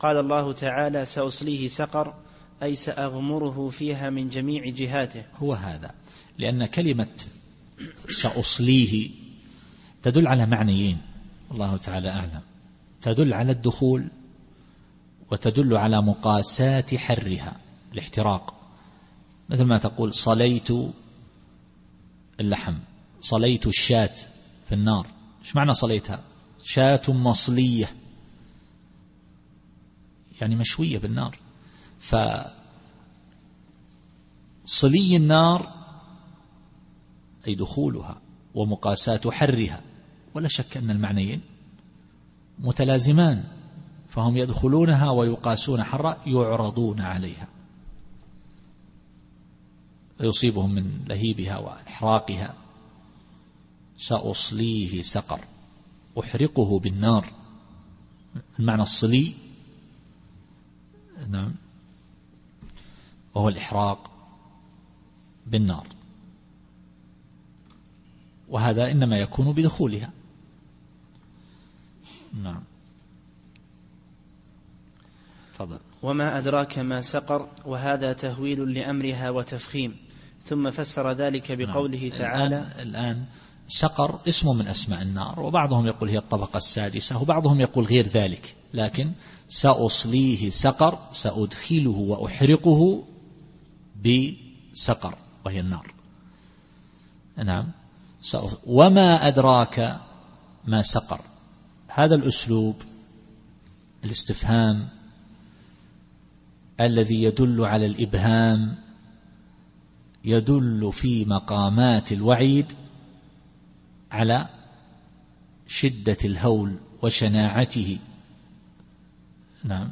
التفسير الصوتي [المدثر / 27]